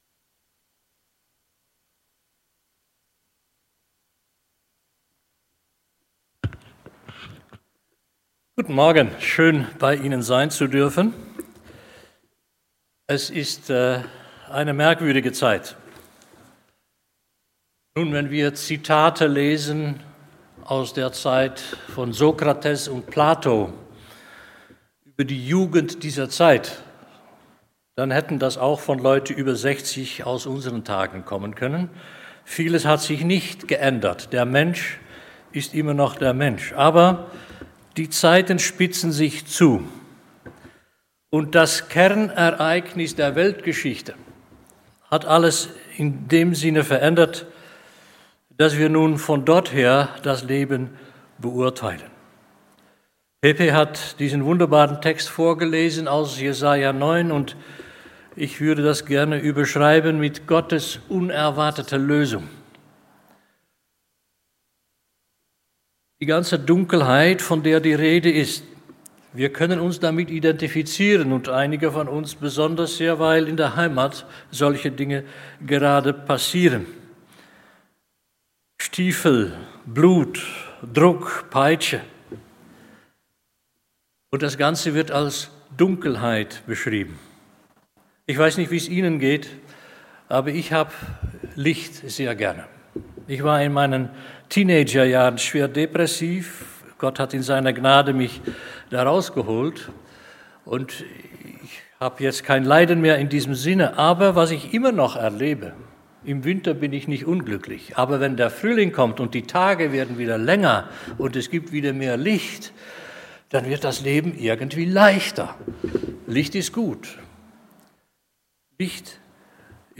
Hier finden Sie die aktuellen Sonntagspredigten der Baptistengemeinde Basel.